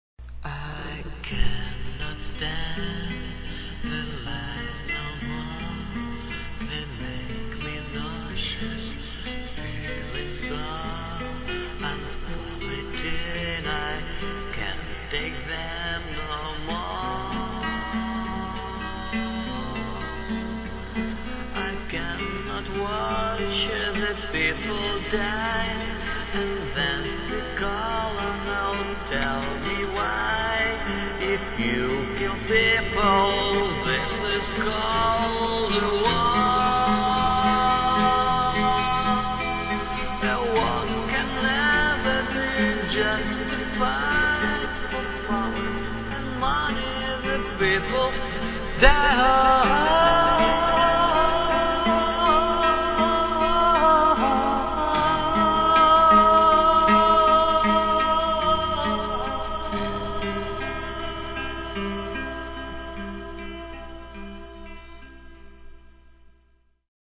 The song was recorded right away, with me being still in my pyjama.